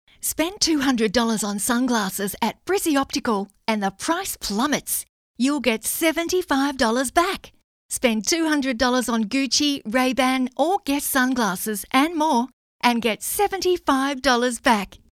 • Hard Sell
• Rode Procaster mic